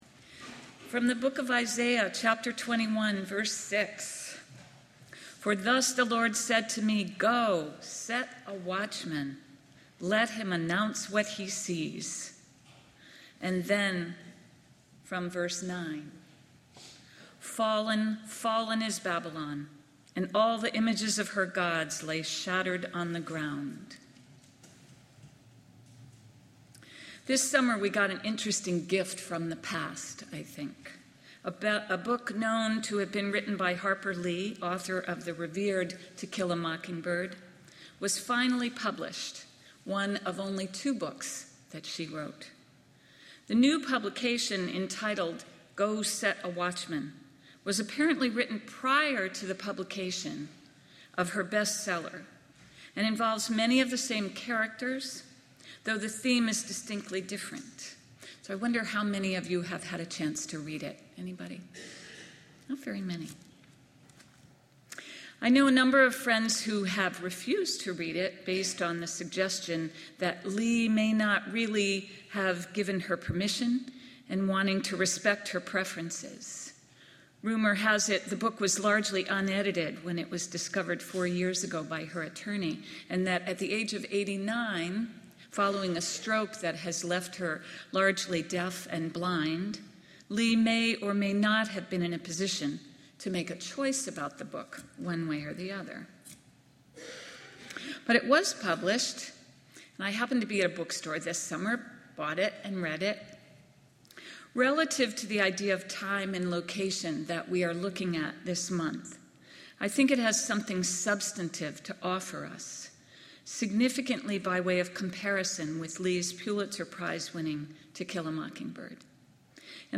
Our joyful voices are joined by a small jazz ensemble with trumpet, guitar, bass, and drums